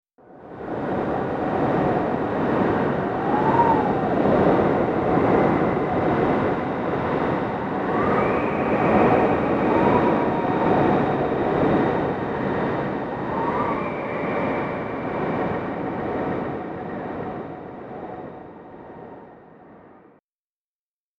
دانلود صدای باد 41 از ساعد نیوز با لینک مستقیم و کیفیت بالا
جلوه های صوتی